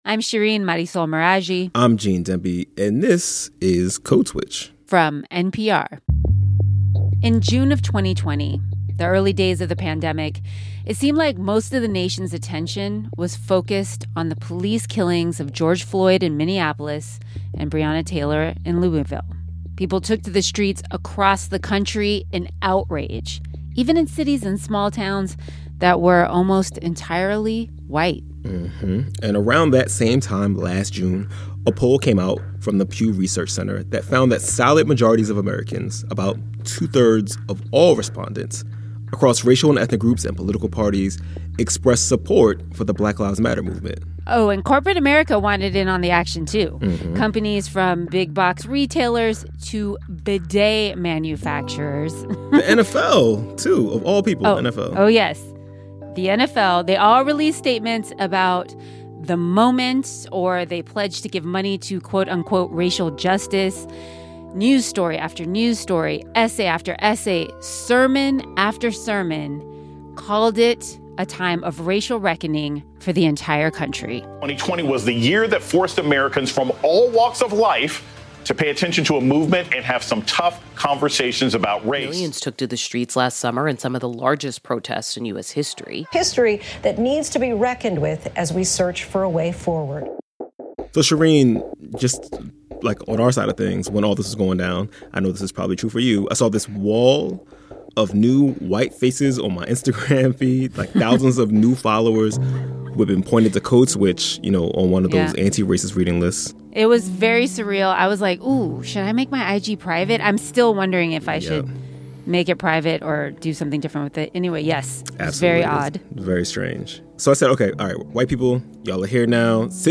A year later, though, polls show that white support for the movement has not only waned, but is lower than it was before. On this episode, two researchers explain why last year so-called racial reckoning was always shakier than it looked.